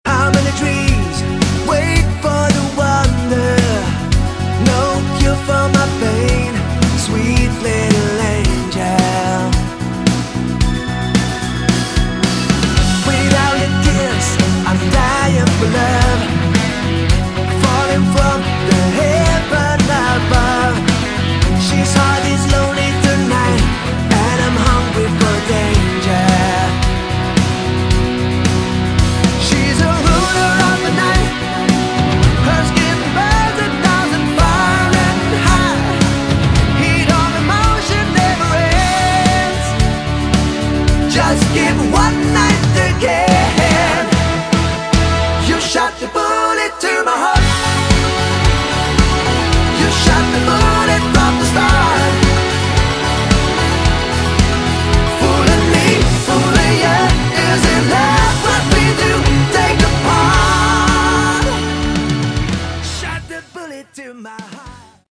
Category: AOR / Melodic Rock
vocals, guitar
bass
keyboards
drums